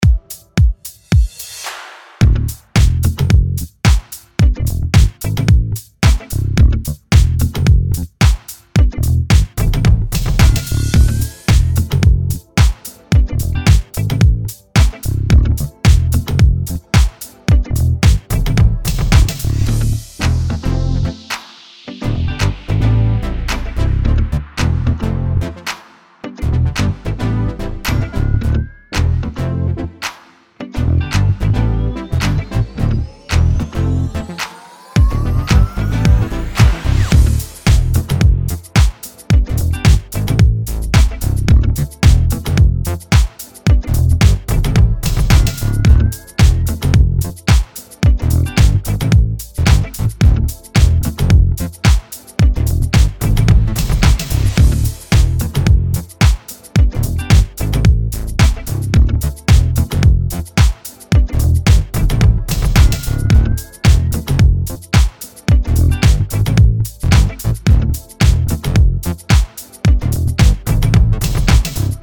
Pop
A Minor